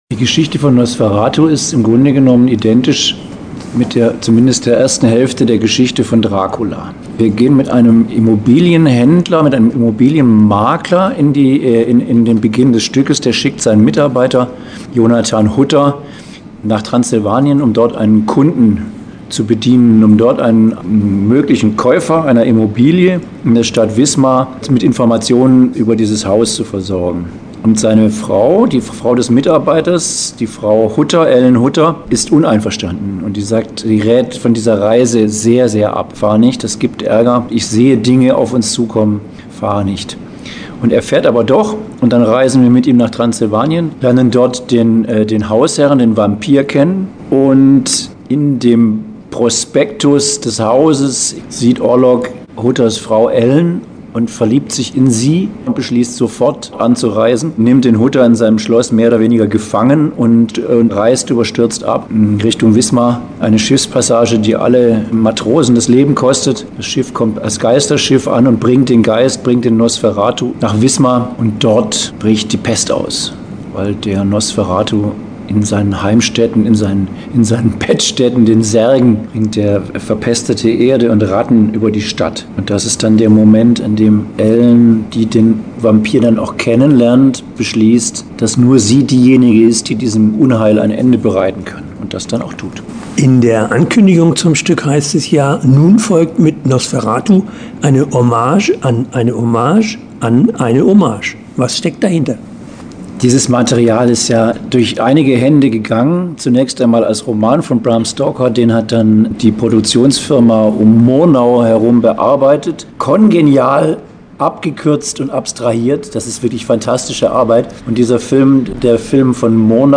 Interview-Staatstheater-Nosferatu.mp3